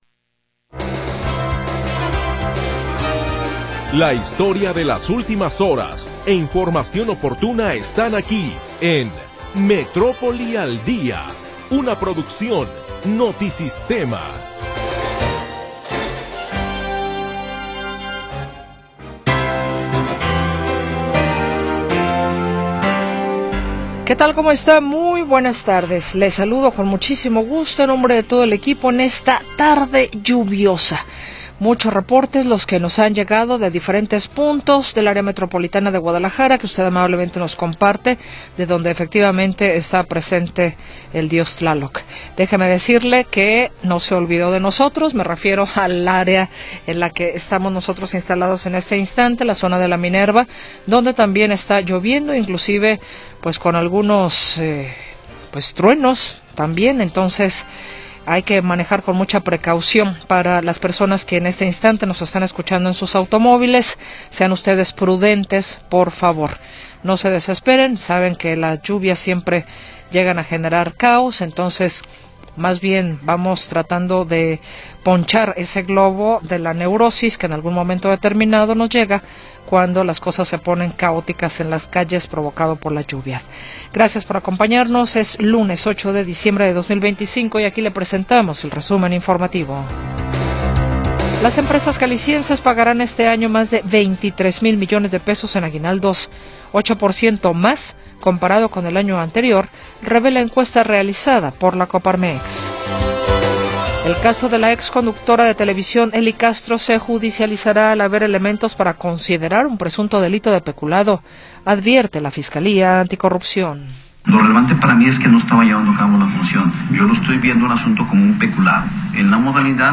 Primera hora del programa transmitido el 8 de Diciembre de 2025.